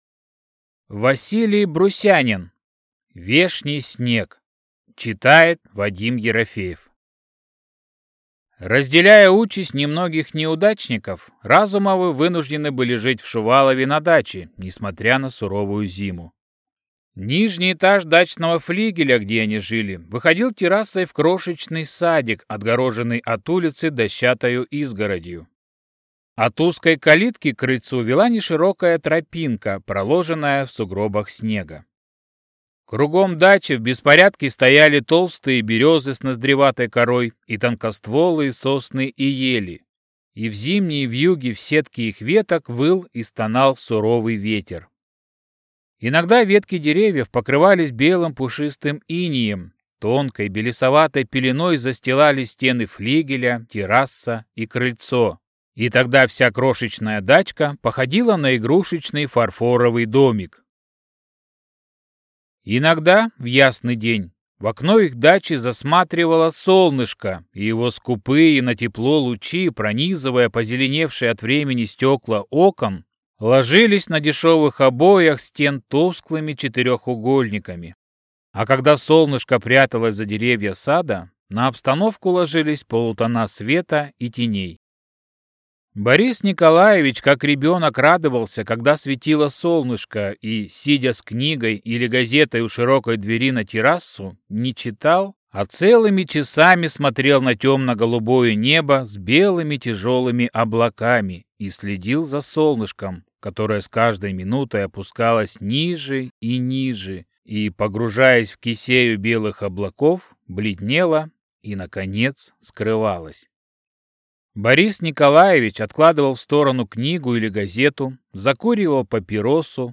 Аудиокнига Вешний снег | Библиотека аудиокниг